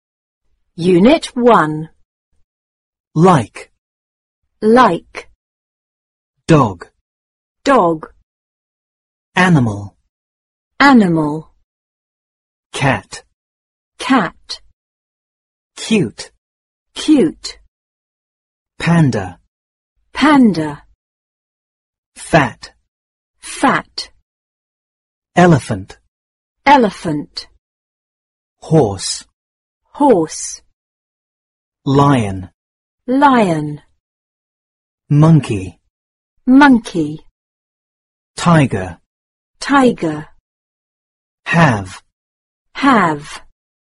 四年级英语上Unit 1 单词.mp3